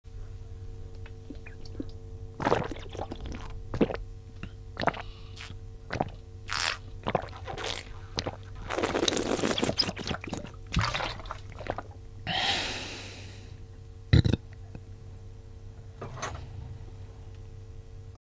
Человек пьет воду и срыгивает